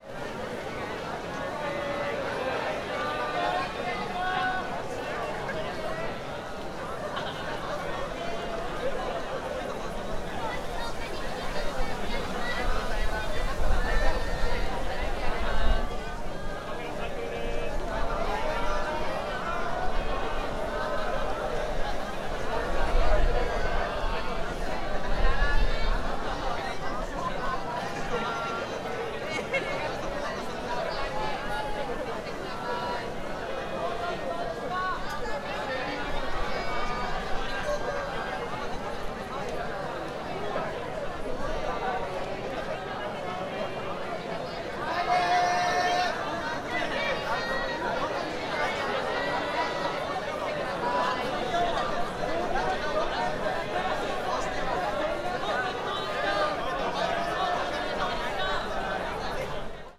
Fukushima Soundscape: Fukushima University